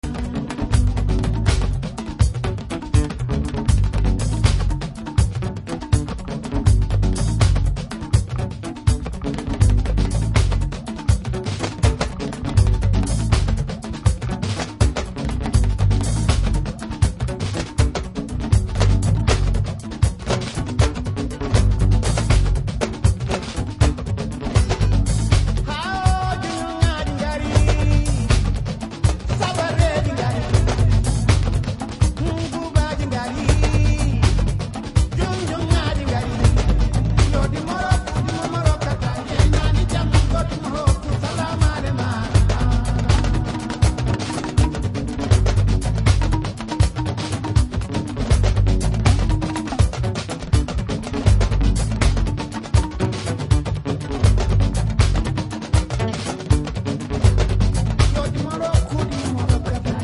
Gravou e produziu in loco composições iluminadas